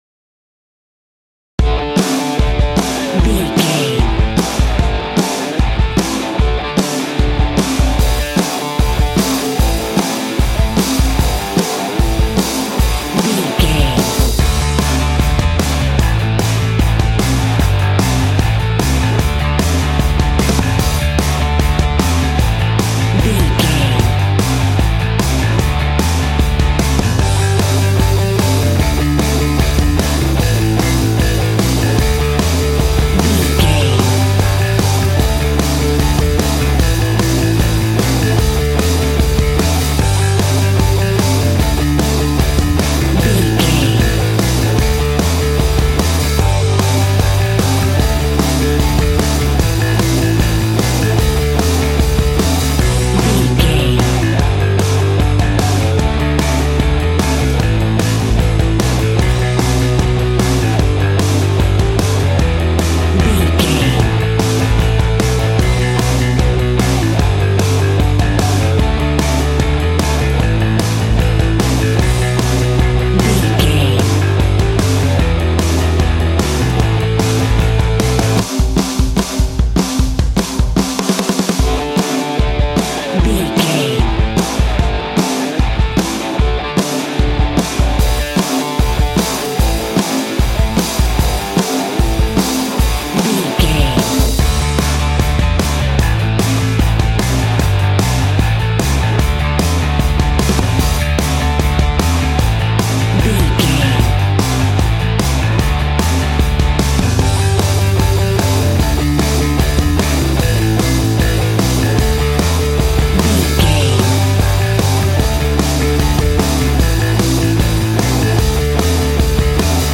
A punk rock track
Aeolian/Minor
groovy
powerful
electric organ
drums
electric guitar
bass guitar